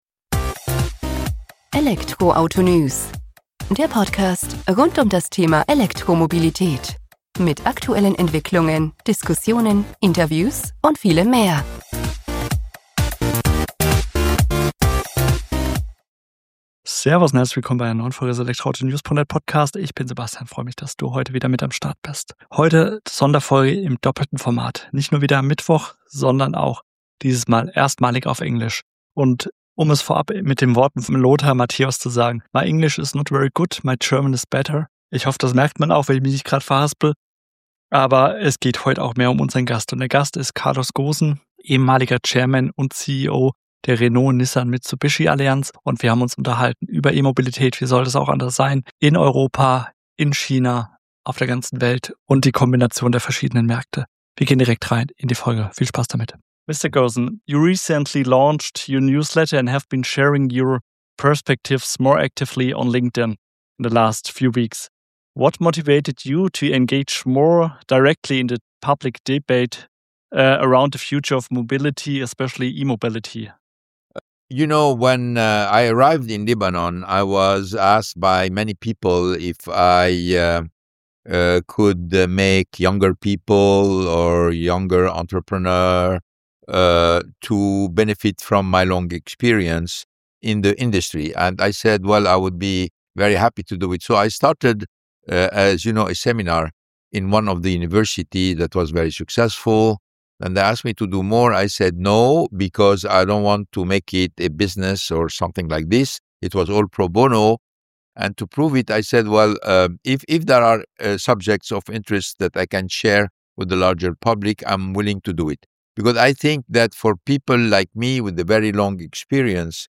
Beschreibung vor 2 Monaten In dieser Podcast-Folge spreche ich mit Carlos Ghosn, einem der einflussreichsten Topmanager der globalen Automobilindustrie.